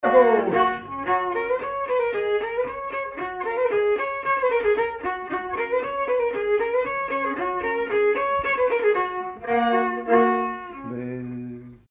Mazurka partie 4
danse : mazurka
circonstance : bal, dancerie
Pièce musicale inédite